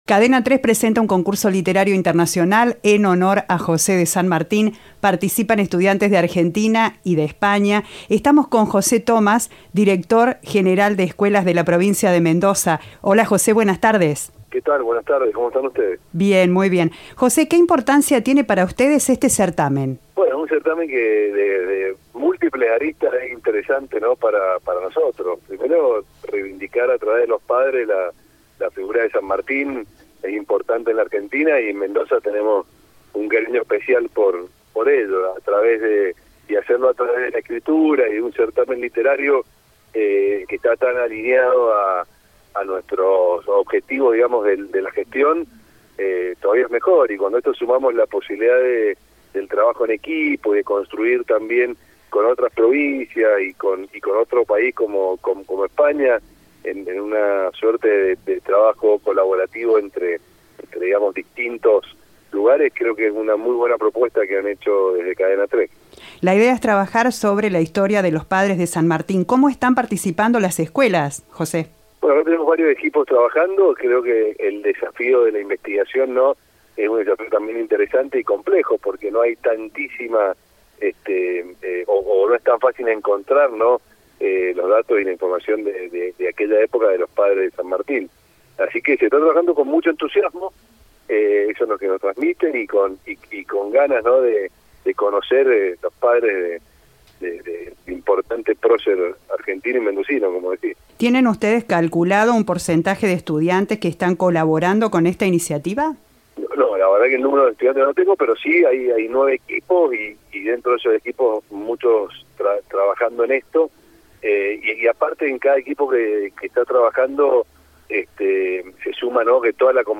El director general de Escuelas de la provincia, José Thomas, contó a Cadena 3 que actualmente hay nueve grupos que participan activamente en la investigación de los datos de los progenitores del prócer argentino.
Entrevista de "Viva la Radio".